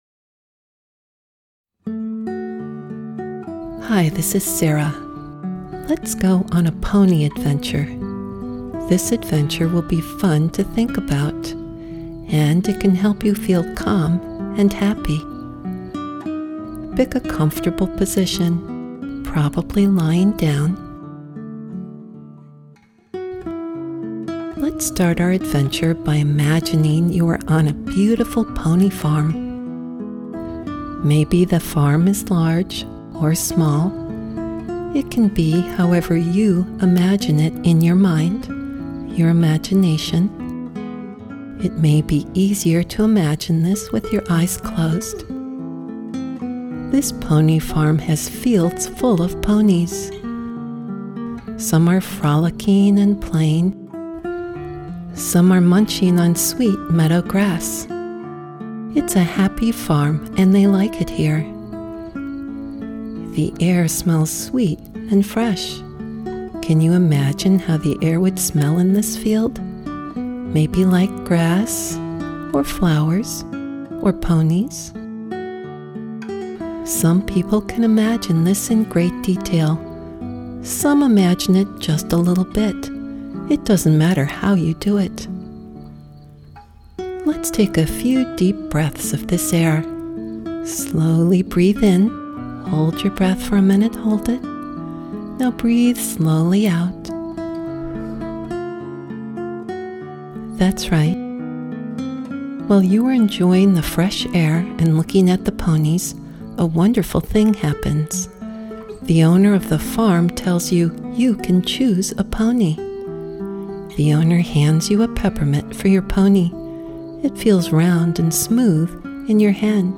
Rainbows — Hospital/Trauma Ages 6–11 • Hospital coping & emotional support A gentle guided meditation created to support children during hospitalization, medical procedures, or recovery from trauma.